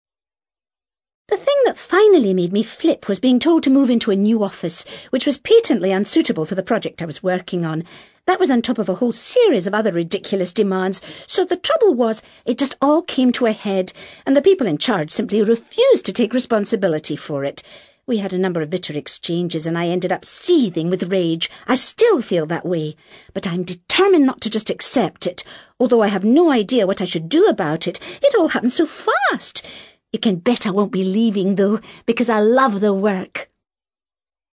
ACTIVITY 162: You will hear five short extracts in which five people are talking about problems related to their work.